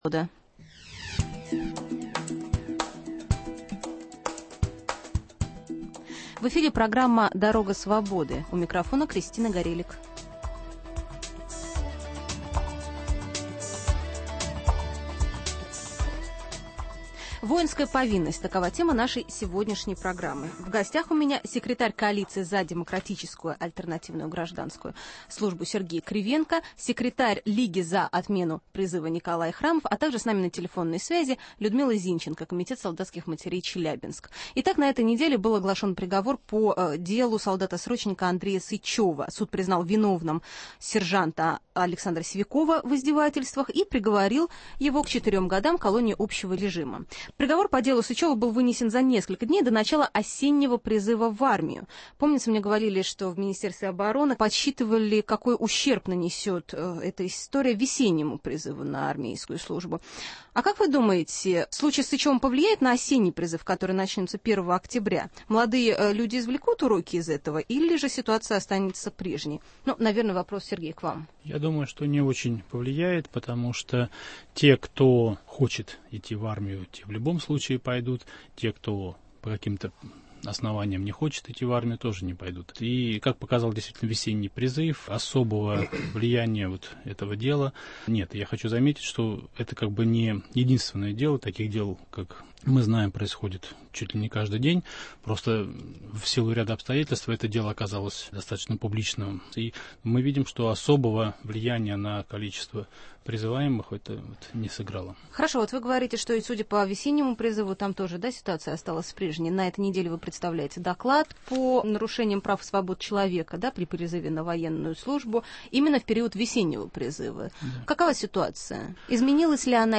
в круглом столе принимают участие: